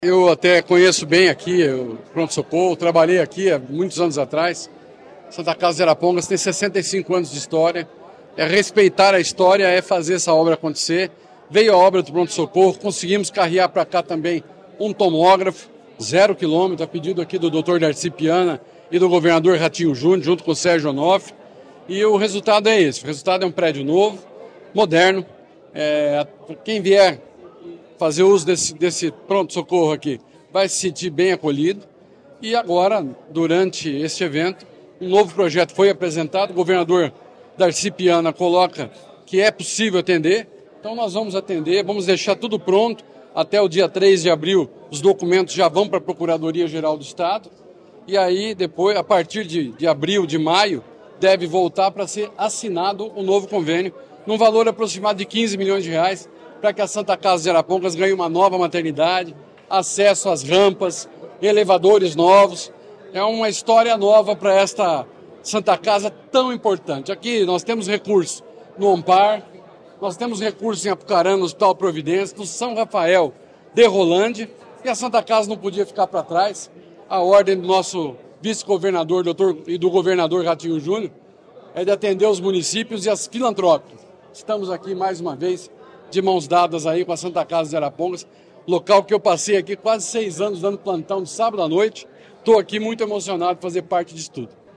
Sonora do secretário da Saúde, Beto Preto, sobre o novo pronto-socorro da Santa Casa de Arapongas